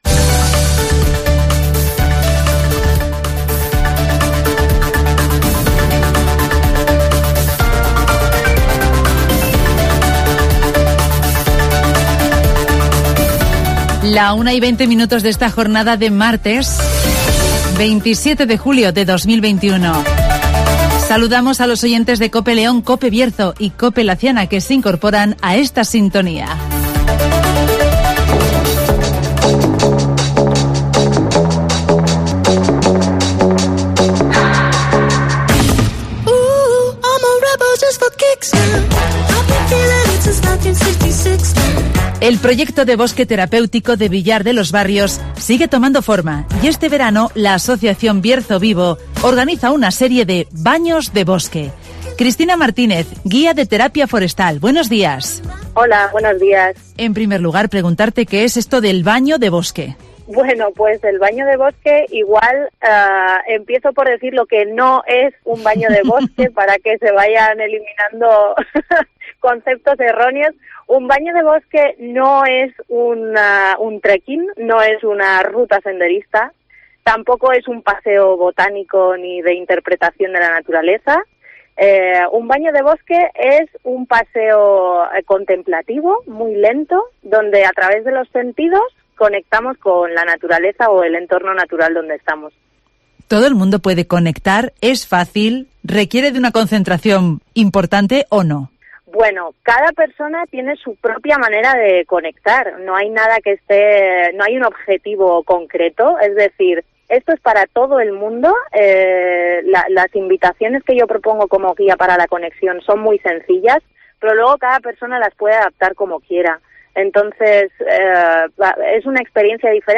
Comienzan los 'Baños de bosque' en el soto de Castaños de Villar de los Barrios (Entrevista